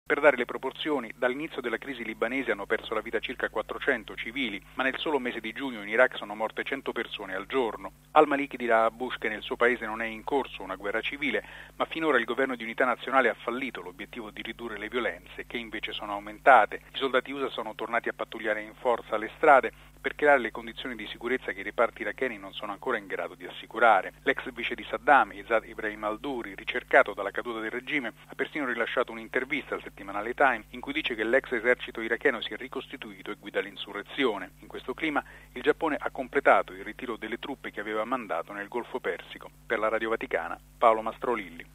E intanto il premier iracheno Al Malìki arriva oggi alla Casa Bianca per discutere con il presidente Bush di una guerra che negli ultimi giorni è stata oscurata dagli scontri in Libano, ma che continua sempre più a mietere vittime. Il servizio